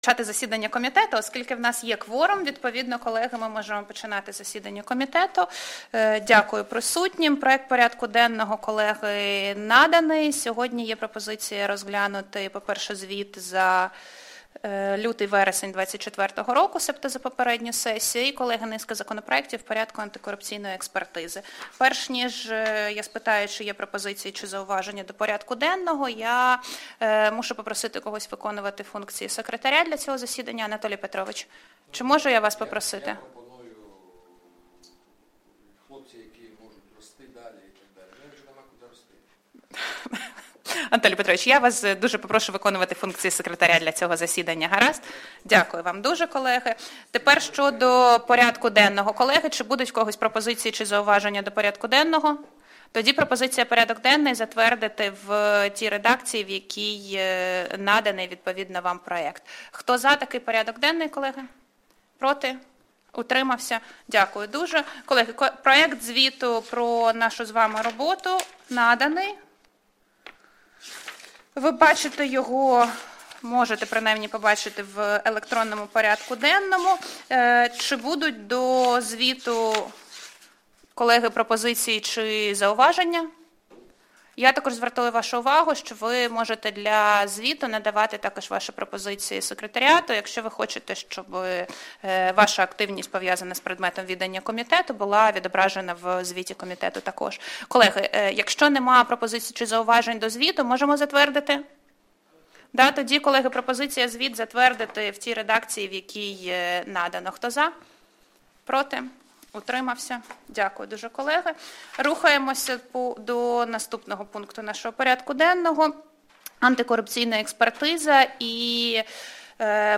Аудіозаписи засідань Комітету за жовтень - грудень 2024 року
Комітет Верховної Ради України з питань антикорупційної політики;засідання Комітету; новини Комітету Верховної Ради України